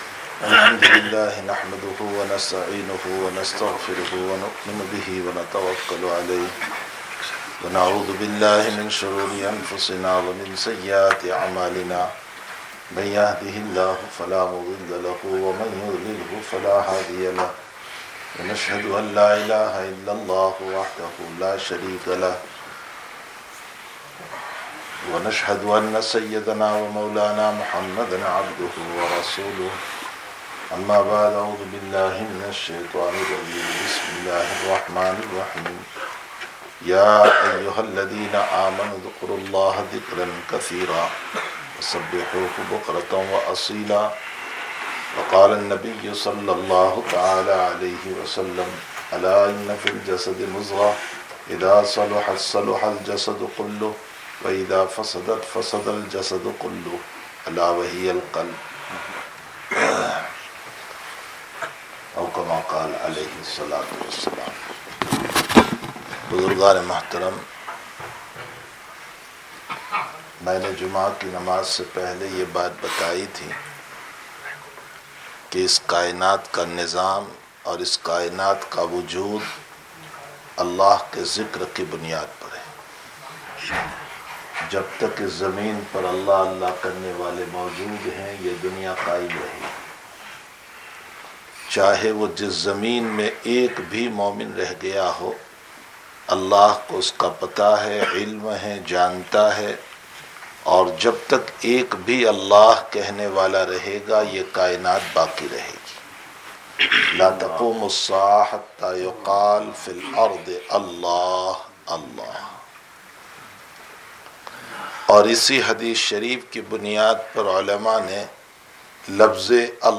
Bayans
Masjid Quba